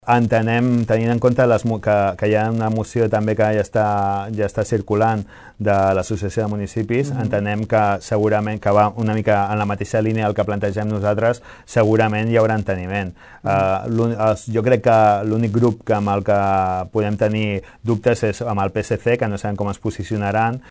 Giralt va afirmar, a la secció de l’entrevista als polítics, que és possible un recolzament majoritari al ple municipal.